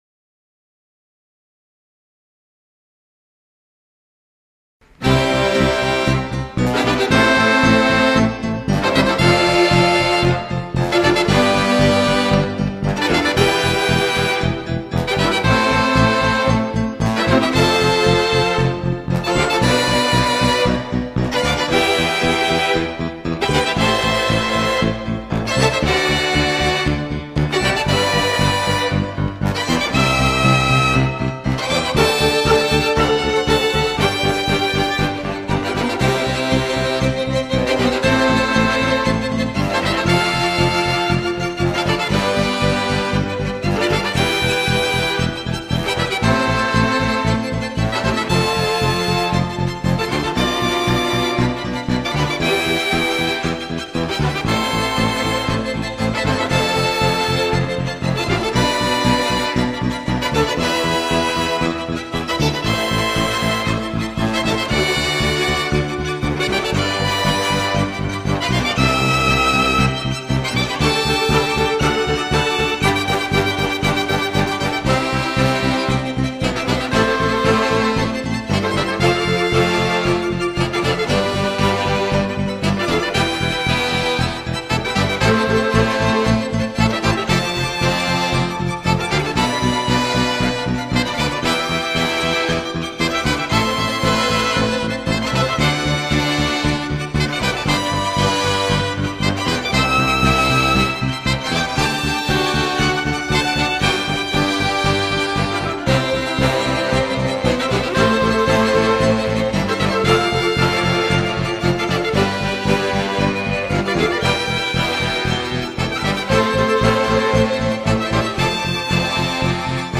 Egregia partitura minimalista